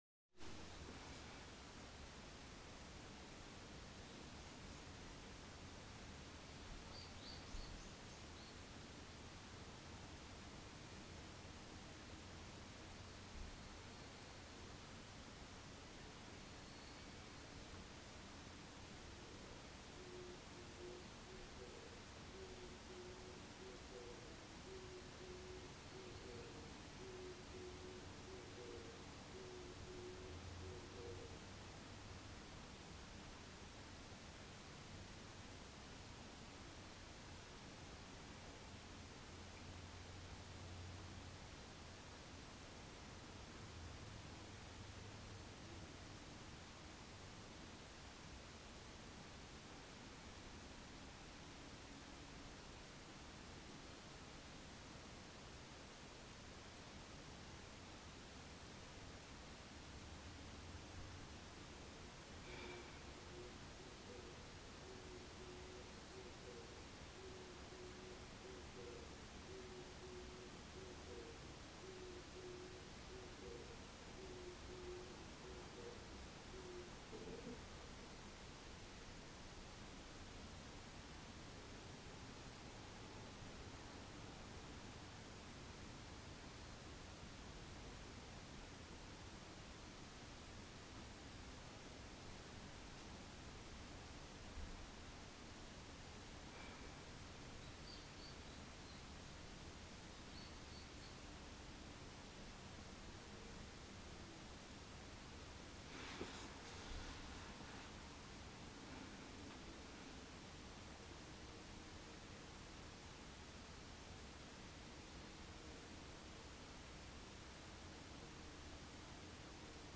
そしてカラスもそうなですが、このキジバトもよく鳴いています。
このキジバト、わたしが鼻で息を吐いたタイミングに鳴いています。
キジバト_200502_0905